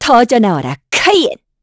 Isolet-Vox_Skill4_kr_b.wav